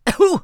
traf_ouch4.wav